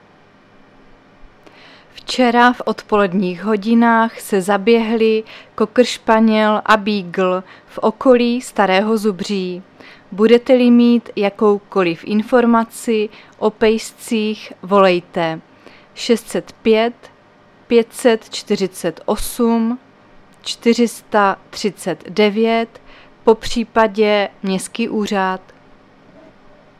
Záznam hlášení místního rozhlasu 17.4.2024
Zařazení: Rozhlas